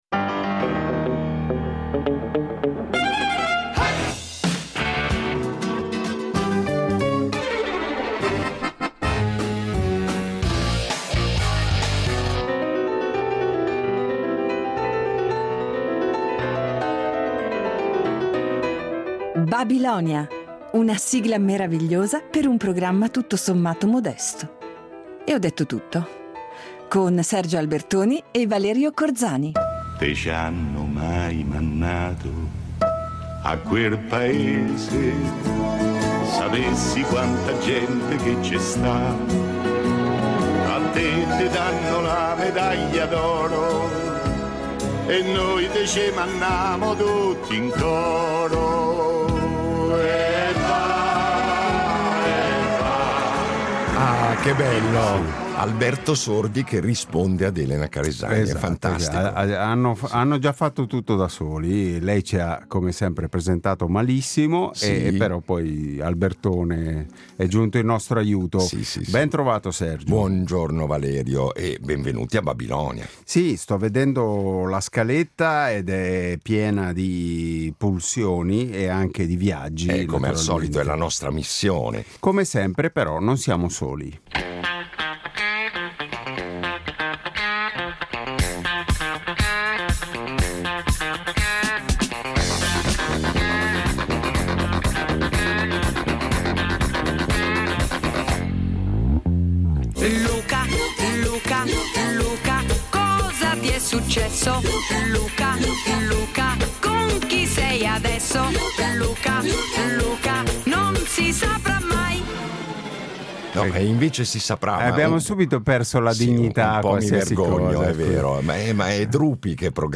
Incontro con il cantautore
Il nostro ospite di oggi è un cantautore la cui poetica è da sempre legata a filo doppio al mondo indipendente e alternativo.